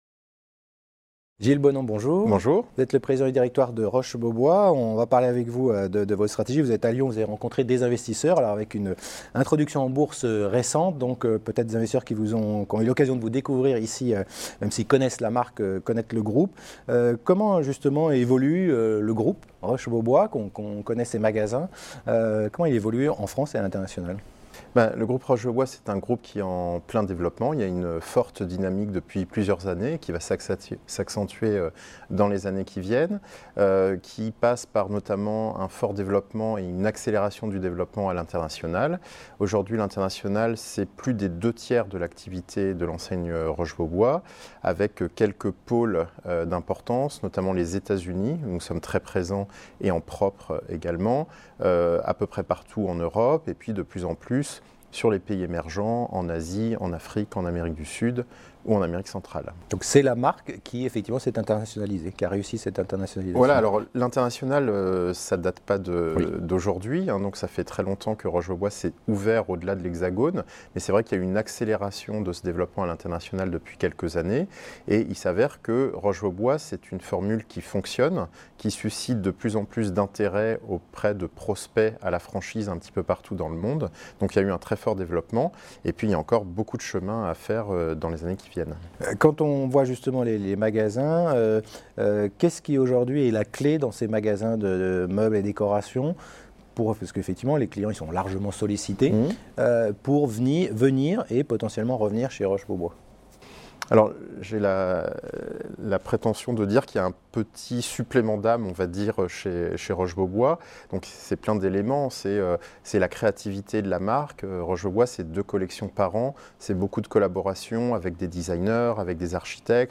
La Web Tv a rencontré les dirigeants au Oddo Forum qui s'est tenu à Lyon le 10 et le 11 janvier